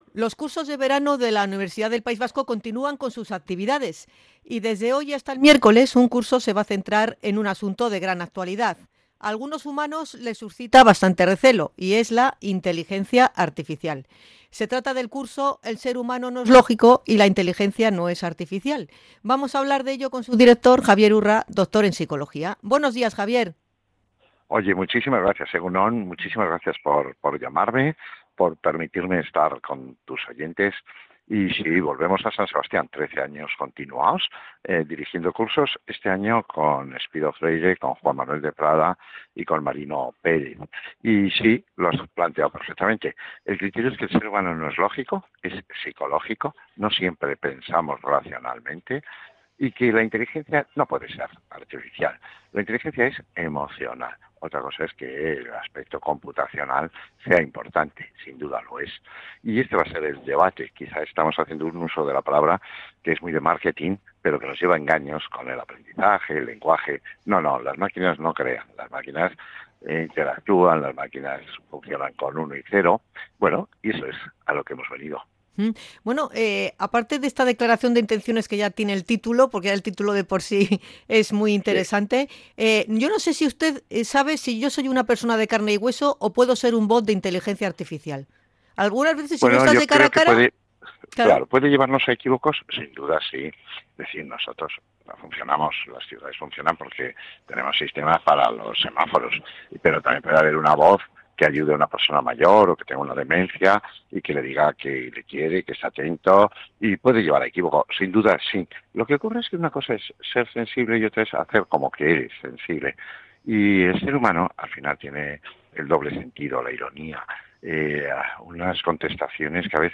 Hablamos con Javier Urra, que dirige un curso de verano de la UPV en el Palacio Miramar
INT.-CURSO-IA-JAVIER-URRA.mp3